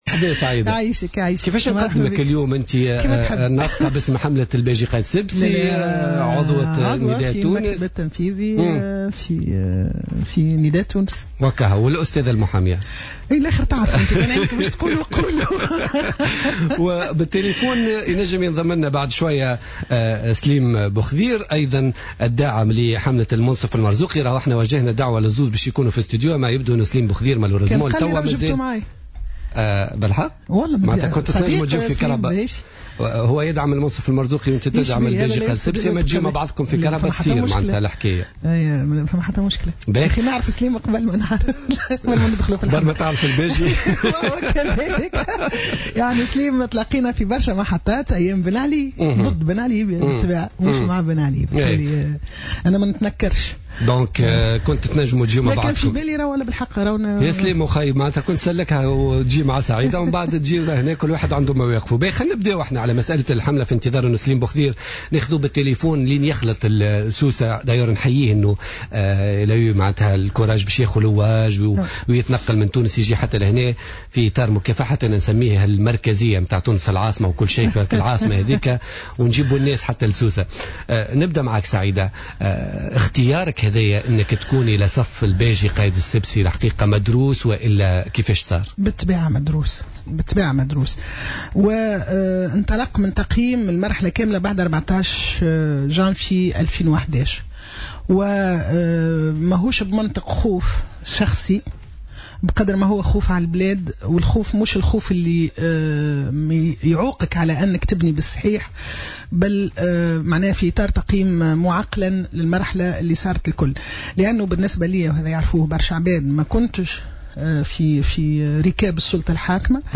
قالت عضو المكتب التنفيذي لنداء تونس سعيدة قراش ضيفة برنامج بوليتيكا اليوم الجمعة 12 ديسمبر 2014 إن المرزوقي ليس عنوانا للدفاع عن الحقوق الإنسان والديمقراطية واصفة اياه بمناضل حقوق الإنسان "سابقا" على حد قولها.